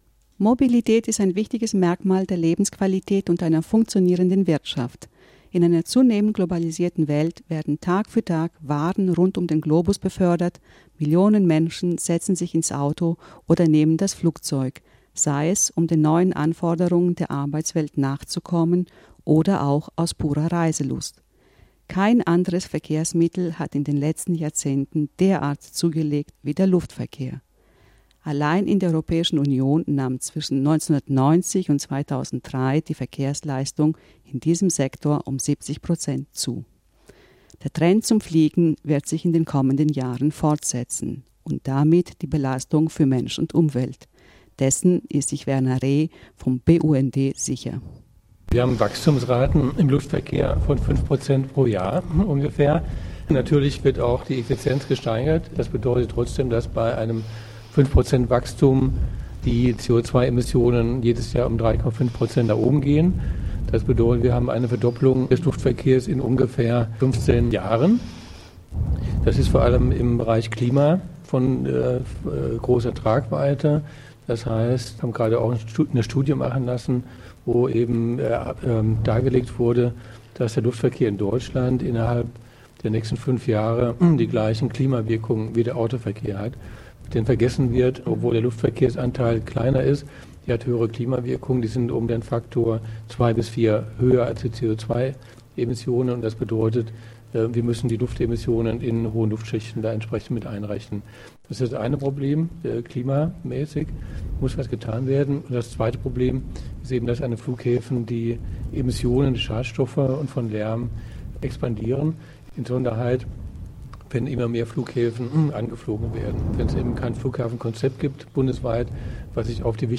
Im Anschluss an die Veranstaltung baten wir drei Teilnehmer zu Wort, daraus entstand das Audio-Podcast „Externe Umweltkosten des Luftverkehrs werden nicht bezahlt″.